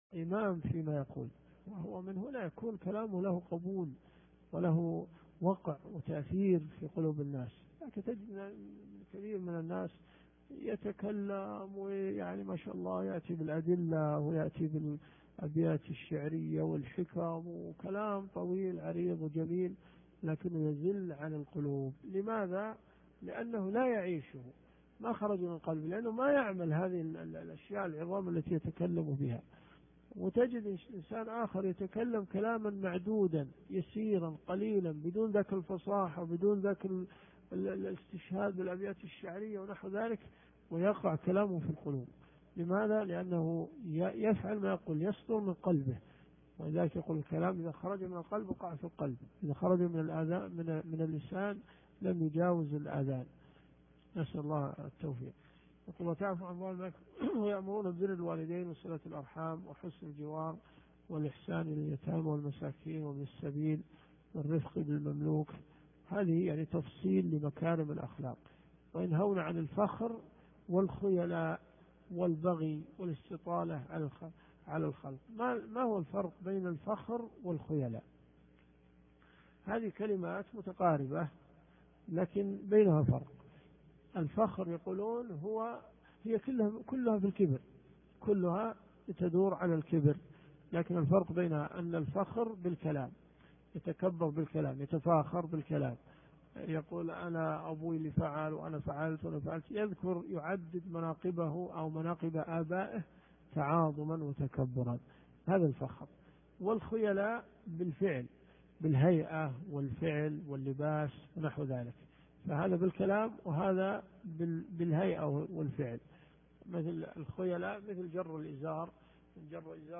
الدروس الشرعية